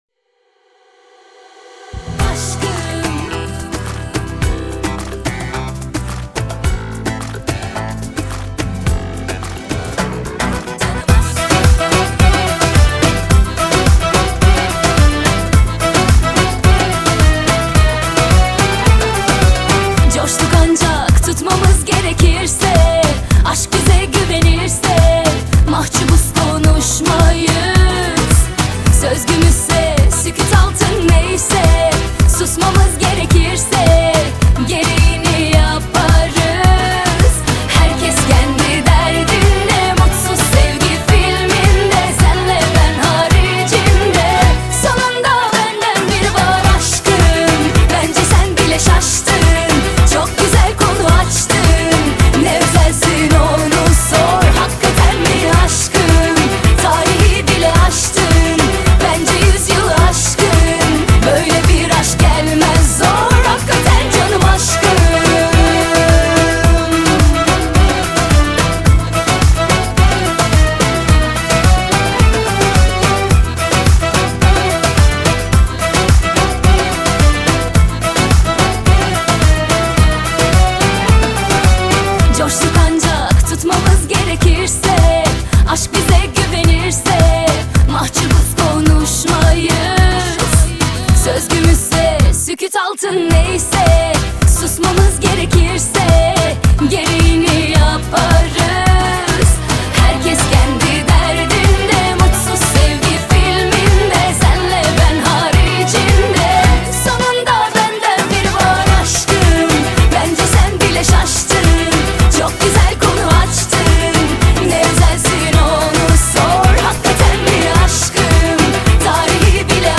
دانلود آهنگ با کیفیت عالی 320